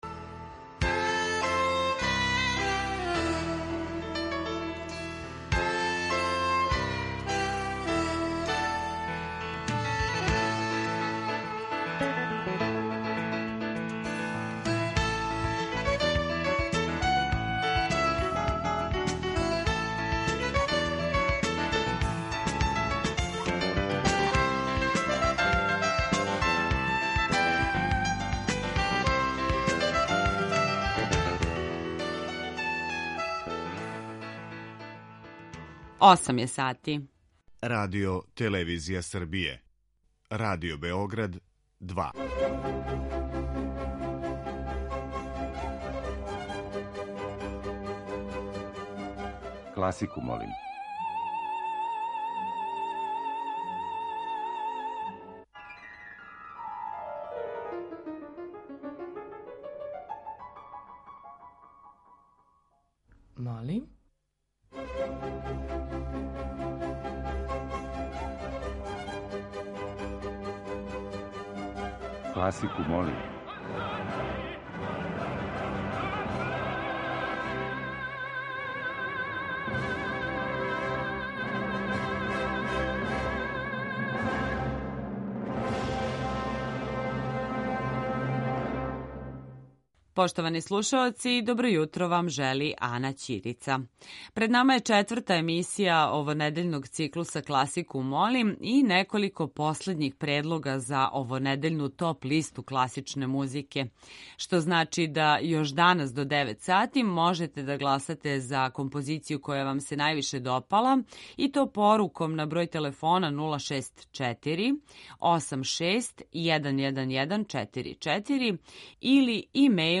Увертире
У јутарњем, једносатном звучном каруселу и ове седмице трагамо за најлепшим композицијама класичне музике и предлажемо музику за ведар почетак дана и добро расположење.